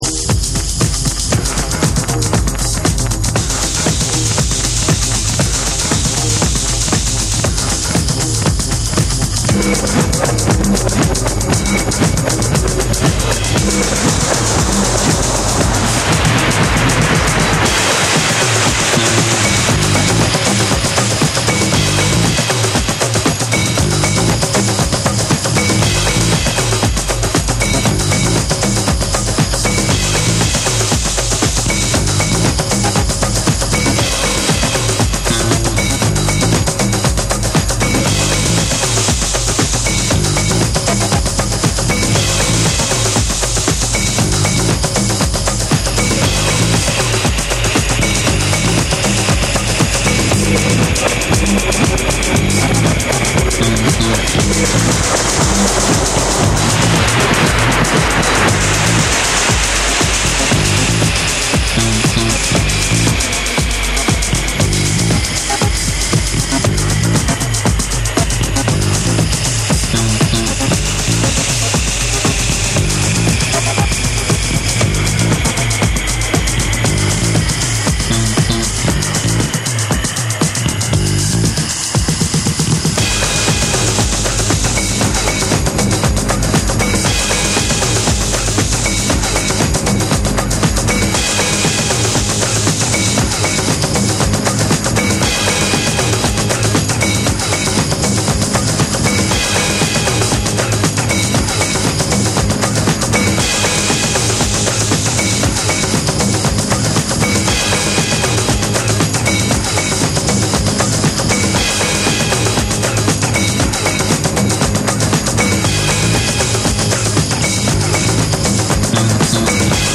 日本のヘヴィー・ロック・バンド
JAPANESE / TECHNO & HOUSE